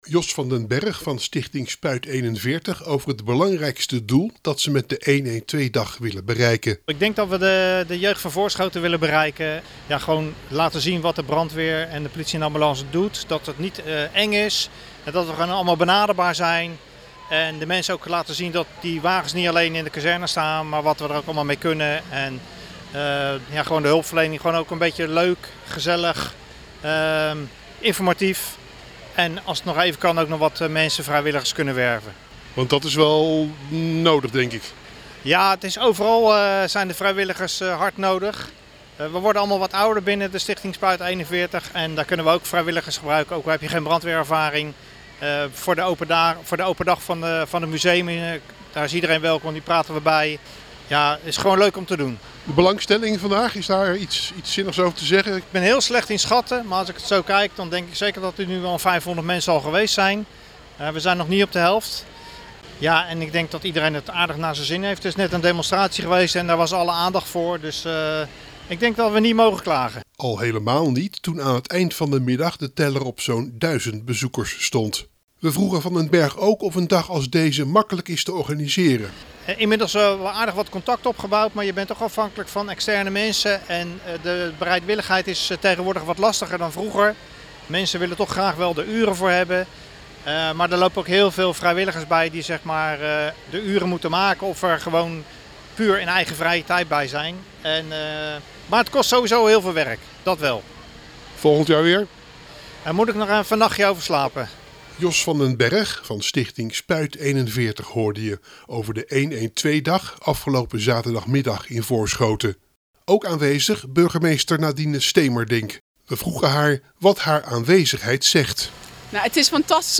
burgemeester van Voorschoten.